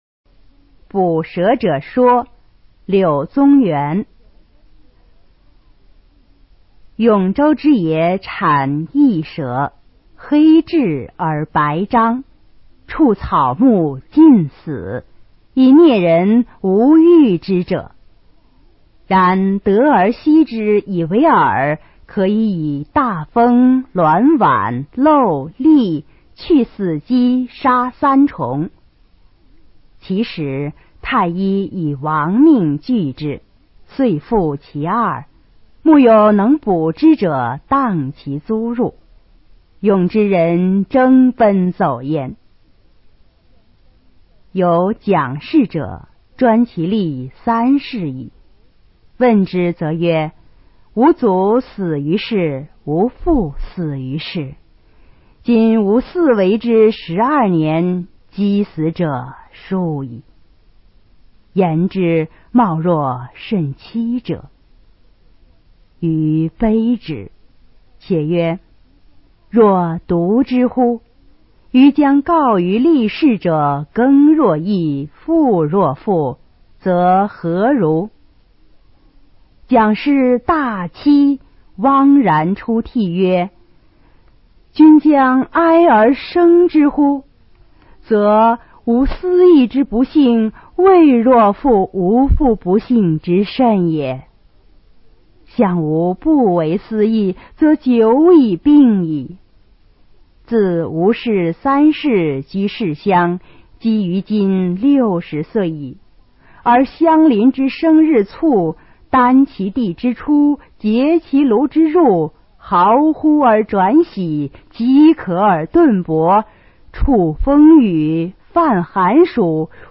《捕蛇者说》原文和译文（含朗读）